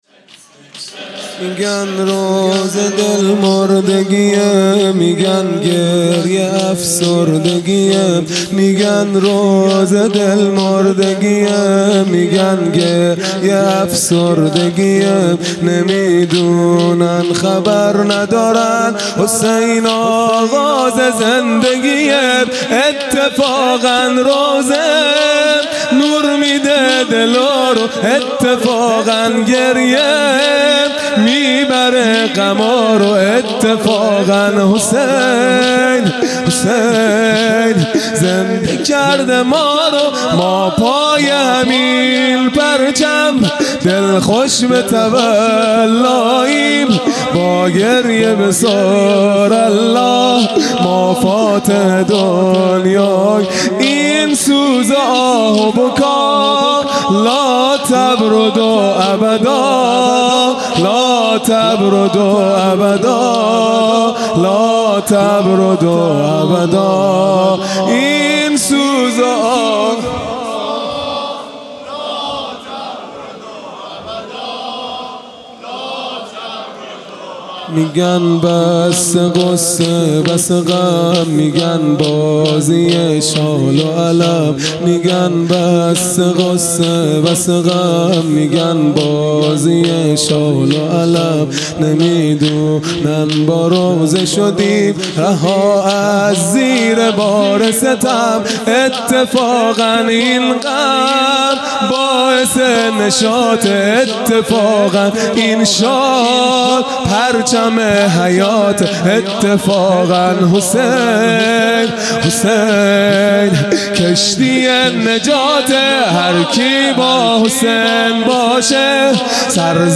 خیمه گاه - هیئت بچه های فاطمه (س) - شور | میگن روضه دل مردگیه
محرم 1441 | شب سوم